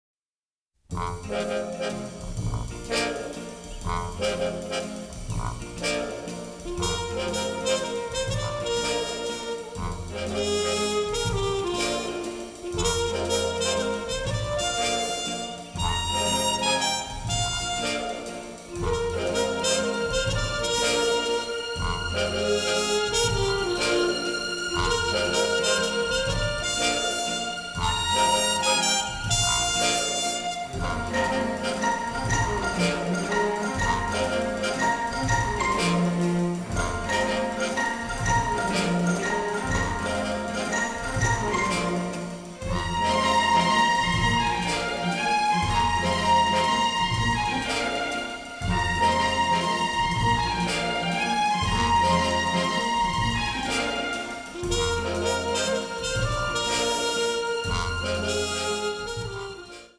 vibrant, funny, powerfully melodic
The recording took place at CTS Studios in Bayswater